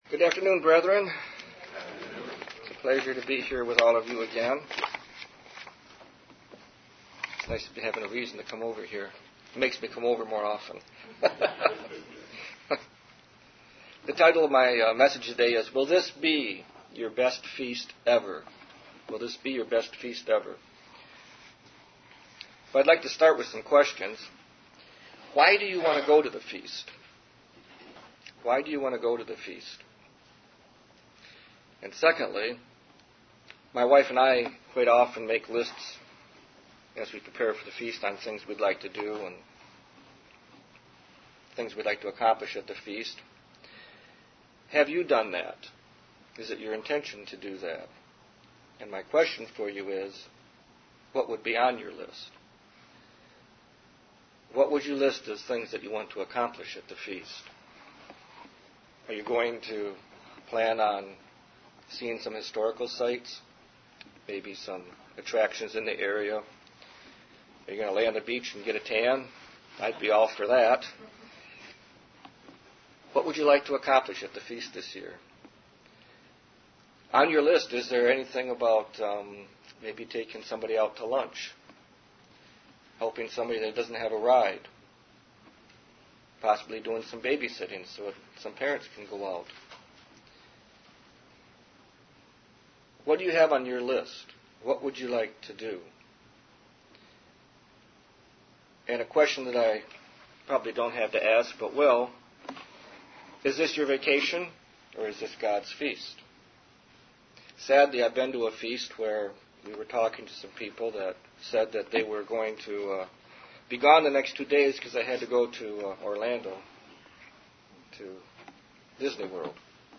Sermons
Given in Detroit, MI